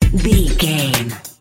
Aeolian/Minor
synthesiser
drum machine
hip hop
Funk
neo soul
acid jazz
energetic
bouncy
Triumphant
funky